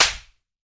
Slap2.wav